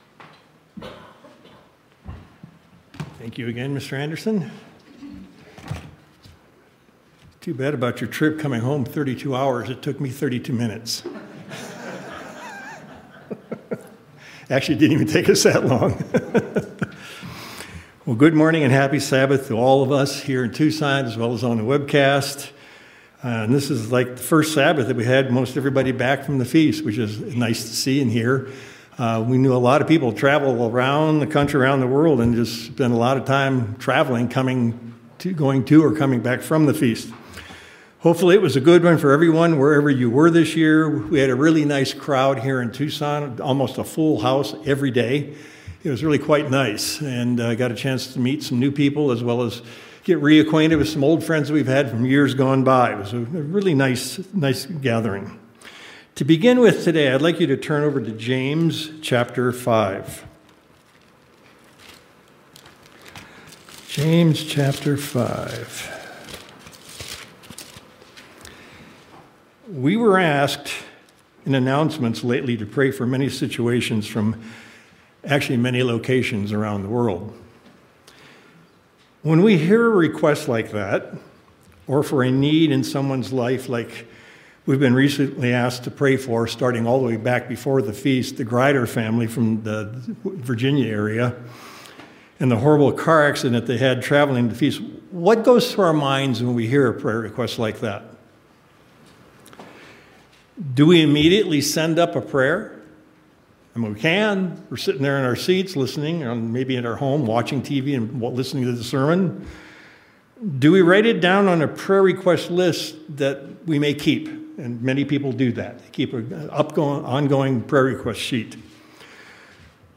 Sermons
Given in Tucson, AZ El Paso, TX